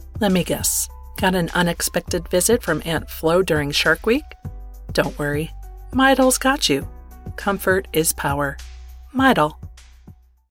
Commercial
North American (General), North American - US Gen American
Midol - Sample with music.mp3